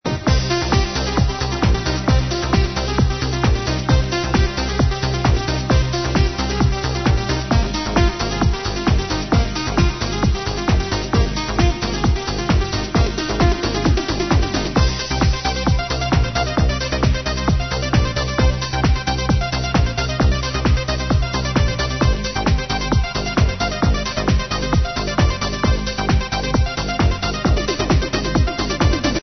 Extended club